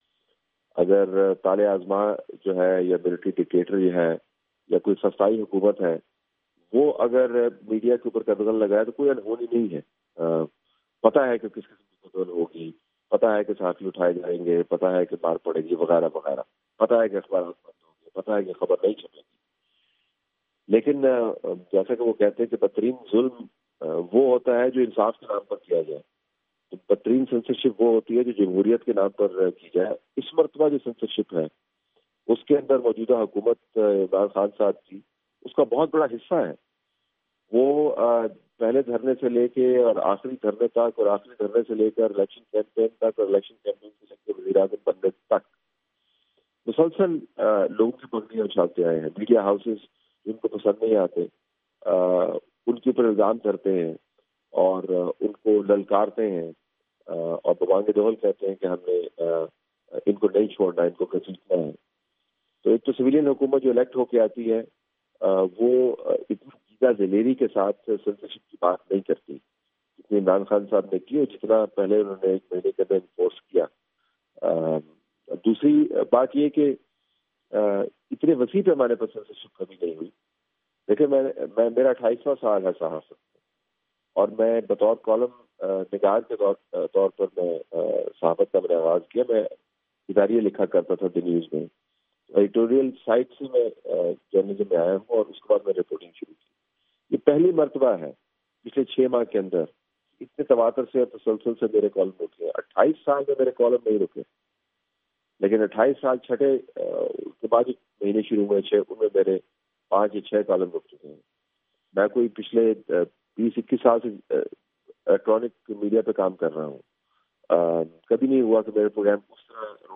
Talat Hussain Interview